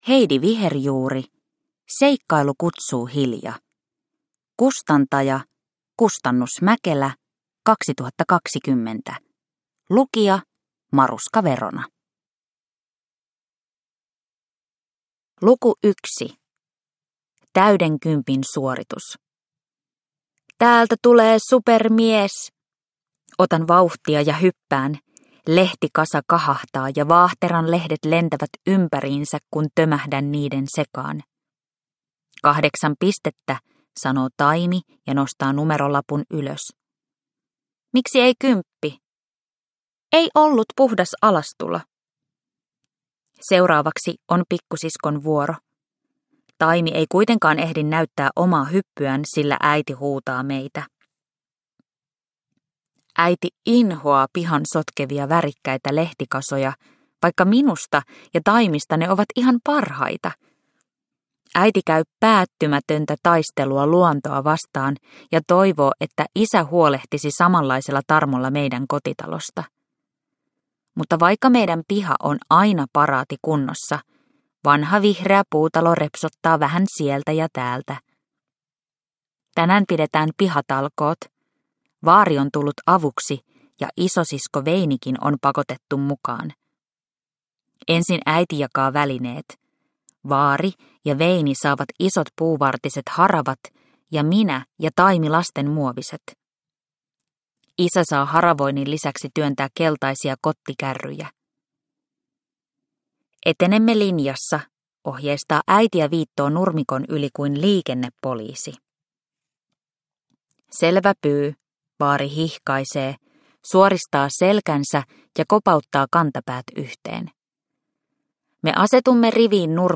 Seikkailu kutsuu, Hilja – Ljudbok – Laddas ner